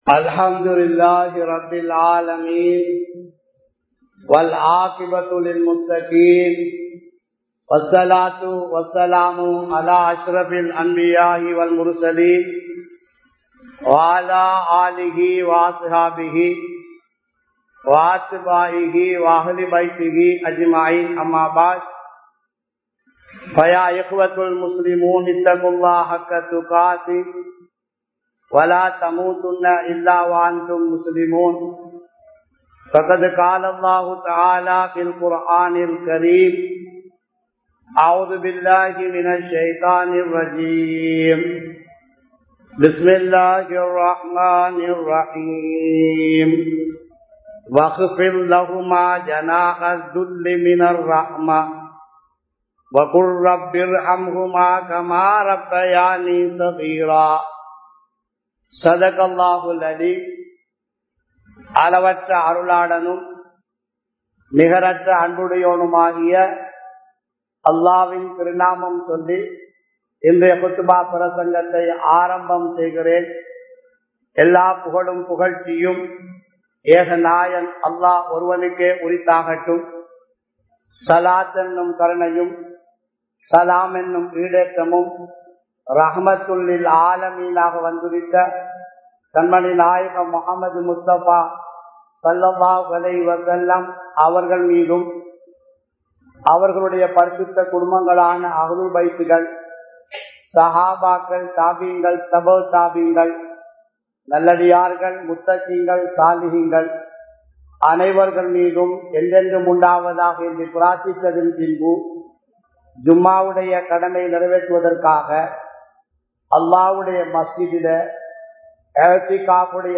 தாயை நேசிப்போம் | Audio Bayans | All Ceylon Muslim Youth Community | Addalaichenai